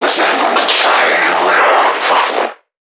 NPC_Creatures_Vocalisations_Infected [49].wav